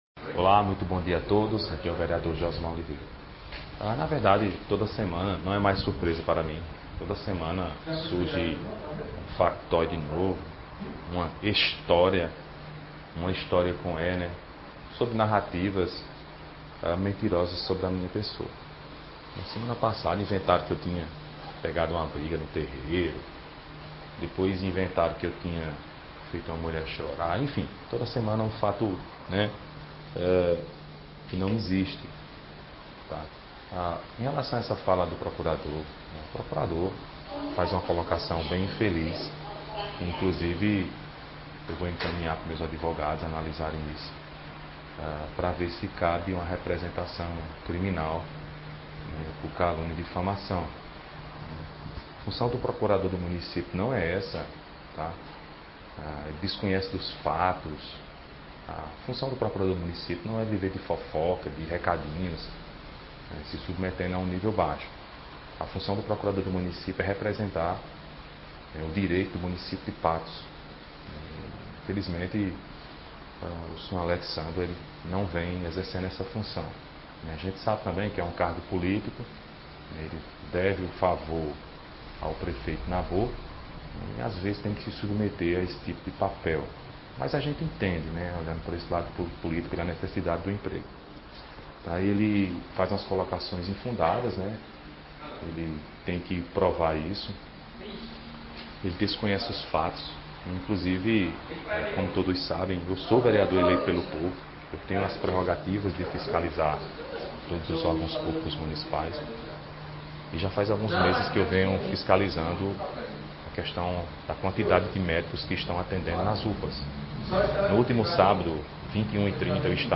O vereador de Patos Josmá Oliveira (Patriota) rebateu em áudio as informações e acusações proferidas pelo procurador da cidade, Alexsandro Lacerda, na tarde desta terça-feira, dia 12 de julho, a respeito de uma fiscalização realizada pelo vereador na UPA do Campo da Liga, no último sábado (9).